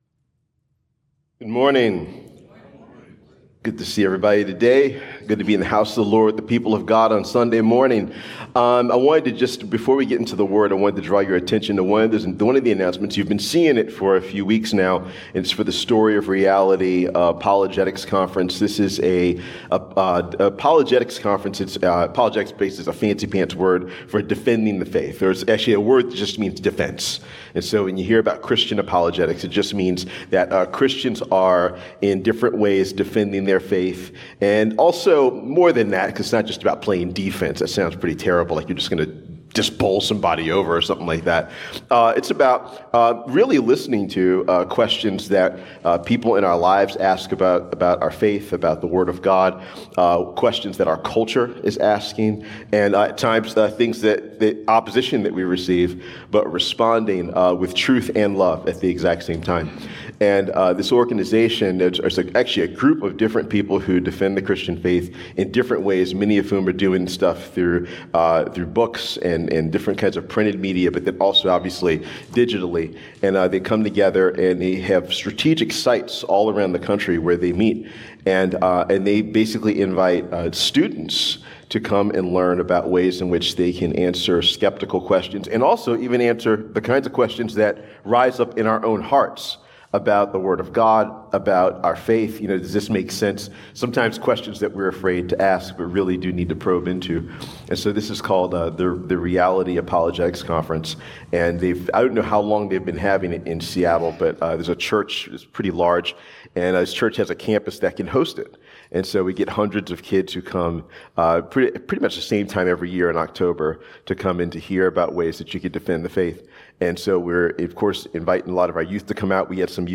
Sermons | Journey Community Church